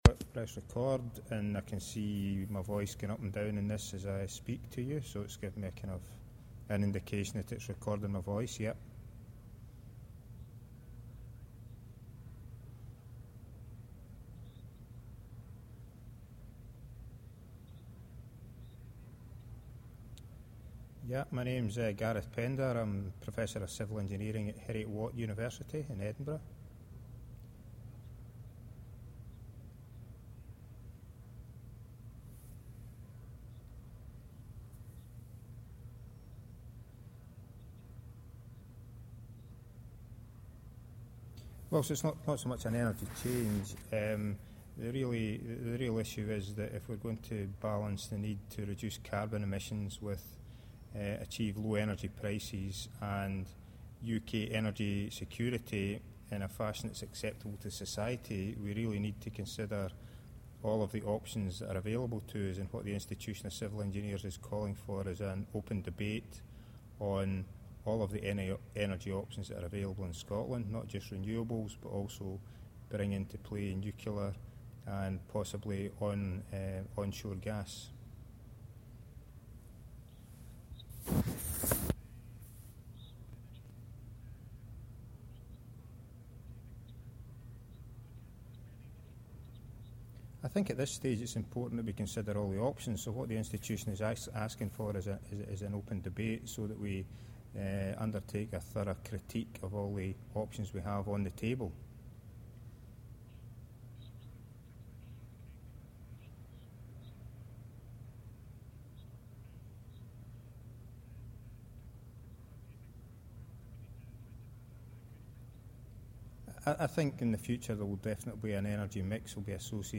ICE Interview